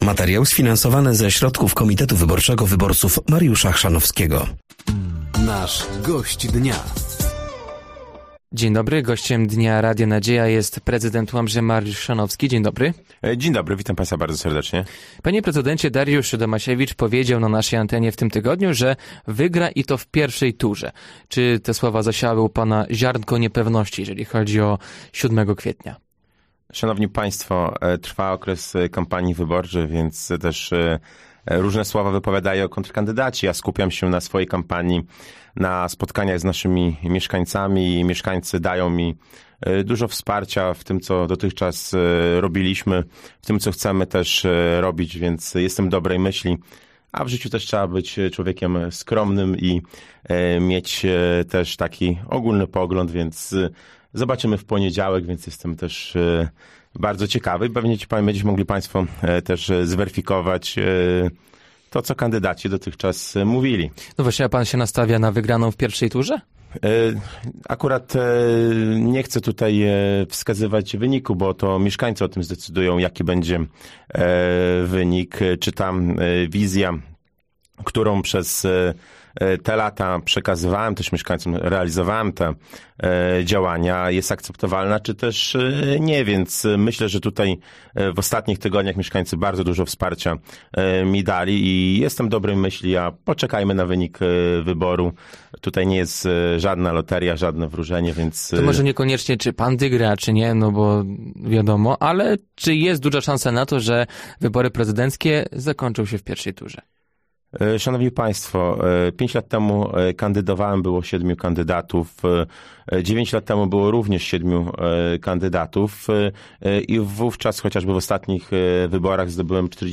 Gościem Dnia Radia Nadzieja był Mariusz Chrzanowski, Prezydent Łomży.